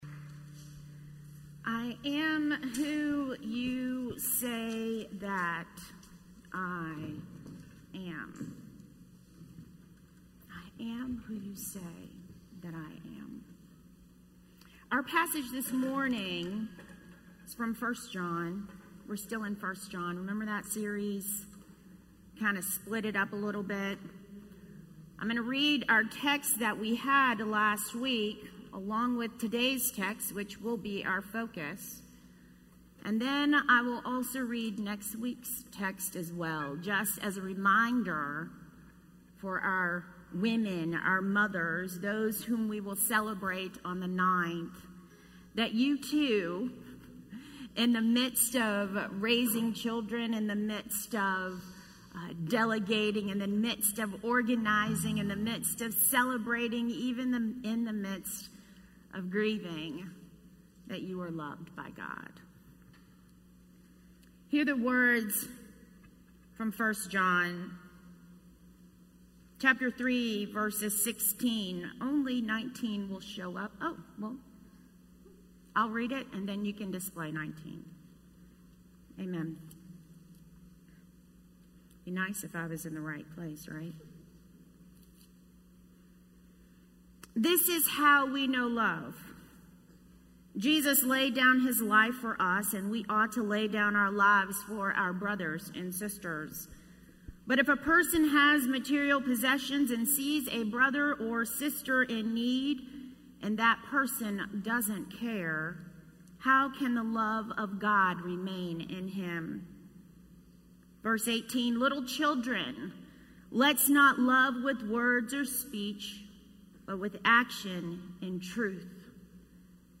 A message from the series "This is How We Know."